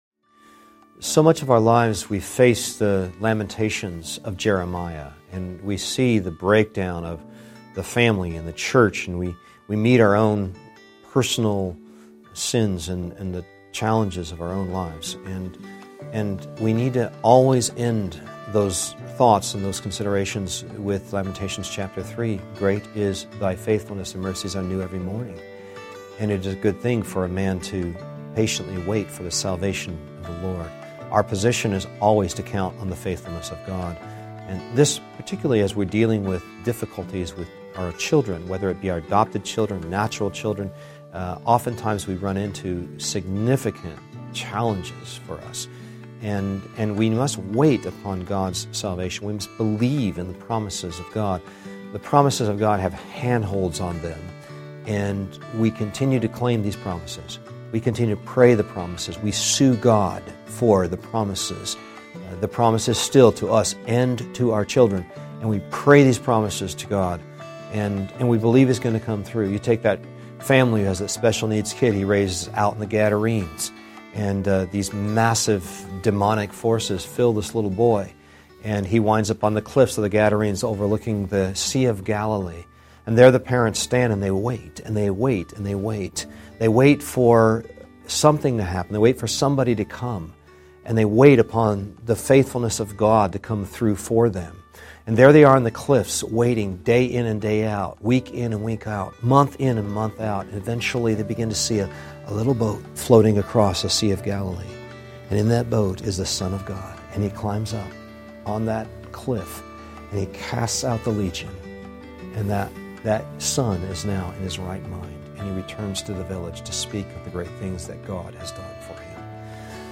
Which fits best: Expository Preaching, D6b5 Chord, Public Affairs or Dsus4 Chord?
Expository Preaching